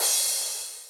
admin-fishpot/b_crash1_v127l4-3o5c.ogg at main